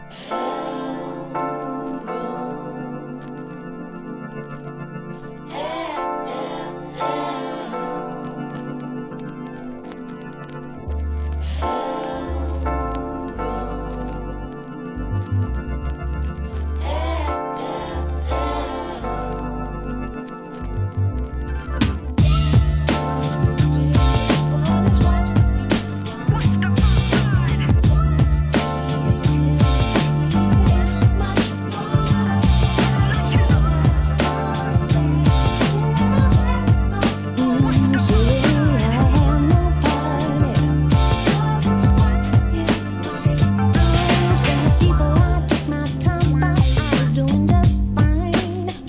HIP HOP/R&B
UKソウル・シンガー